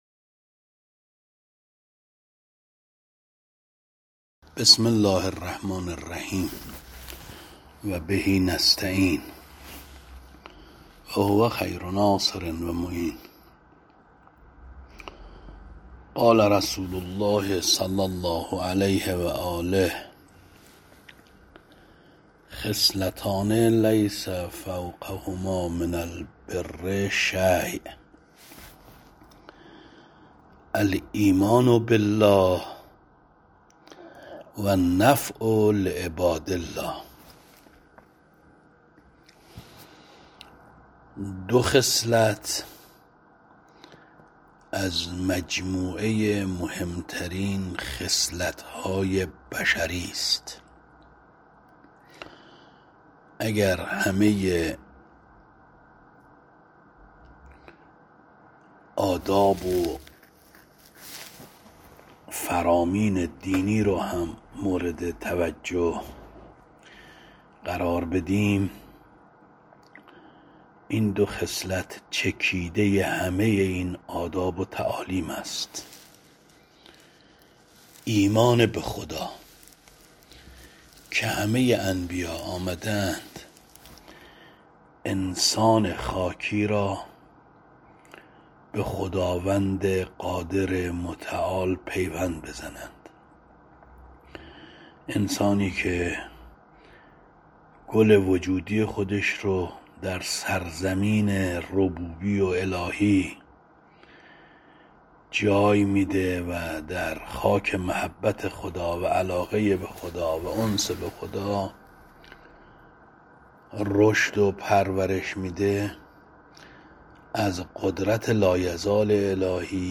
درس خارج